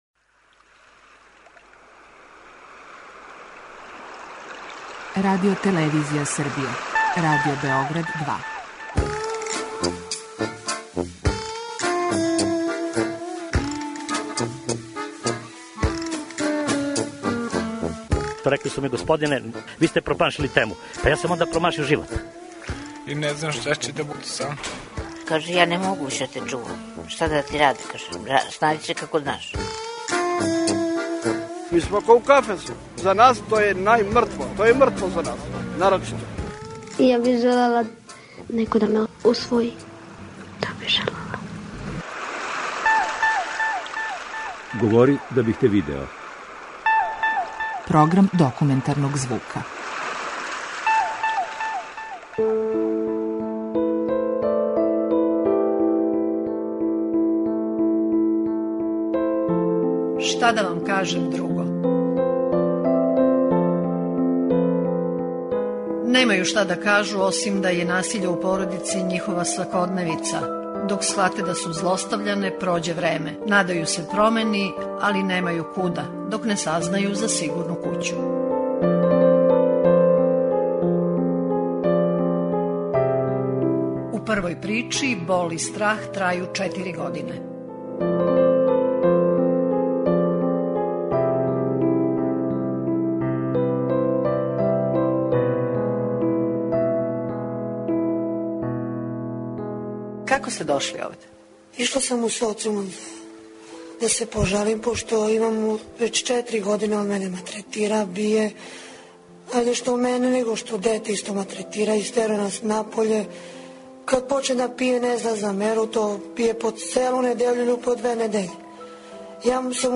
Документарни програм
У сигурној кући у Нишу, заштиту је потражило много жена. У репортажи Шта да вам кажем друго, о својим животним судбинама, малтретирању које су трпеле, последицама, напорима да напусте своје мужеве и садашњим околностима, говориће две жене које су склониште пронашле у овој установи.